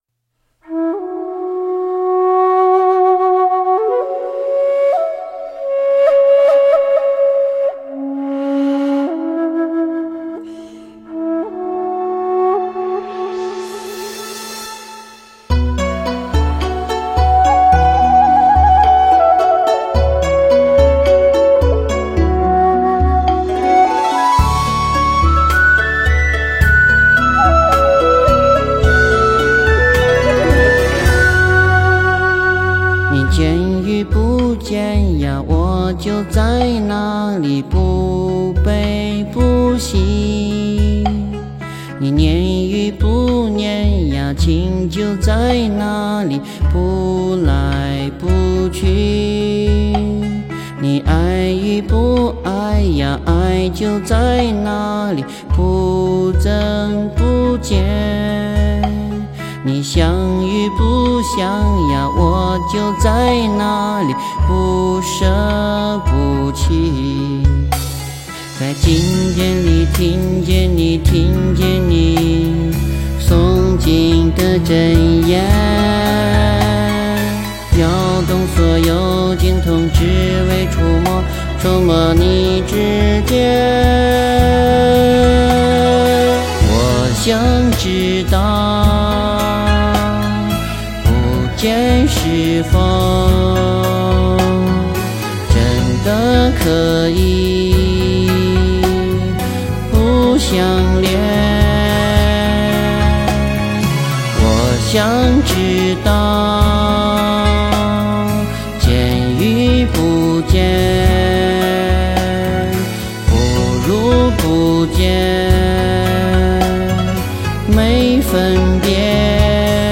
佛音 诵经 佛教音乐 返回列表 上一篇： 观音赞 下一篇： 绿度母心咒 相关文章 药师佛心咒(长咒